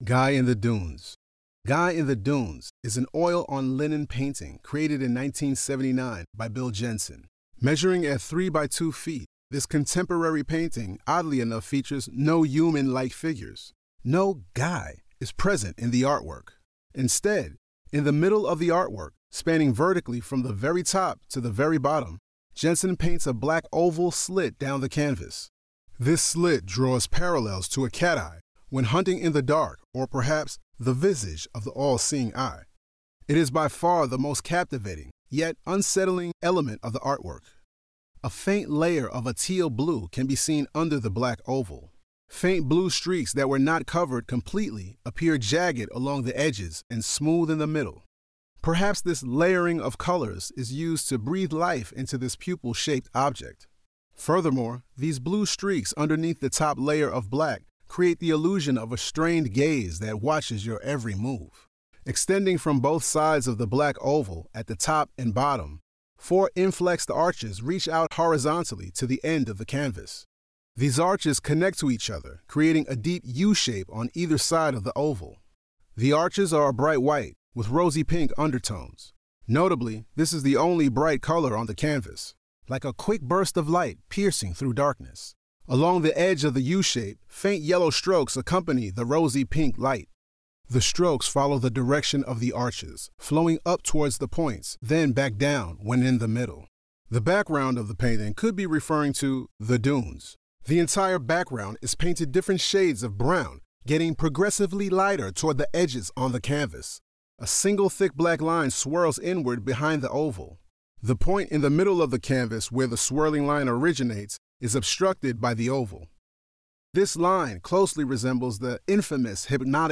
Audio Description (03:00)